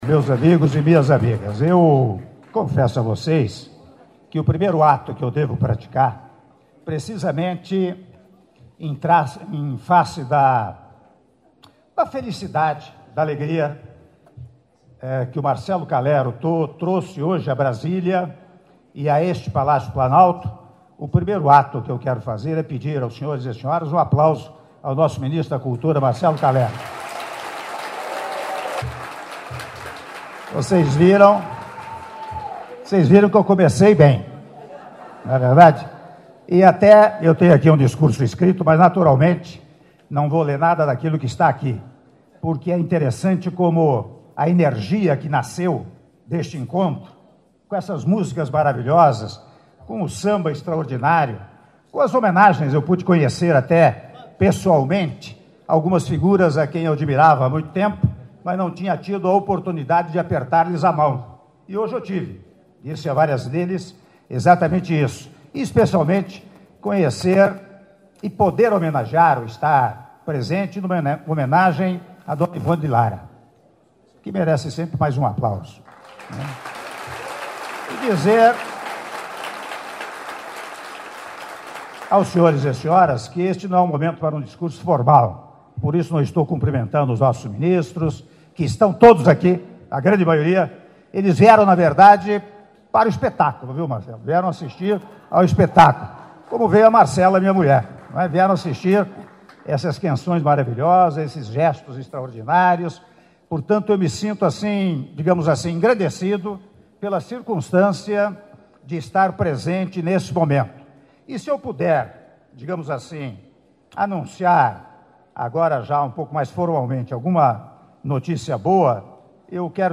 Áudio do discurso do presidente da República, Michel Temer, durante cerimônia de Entrega da Ordem do Mérito Cultural 2016 – Dona Ivone Lara - Brasília/DF (05min16s)